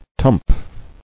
[tump]